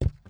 Footstep_Wood 06.wav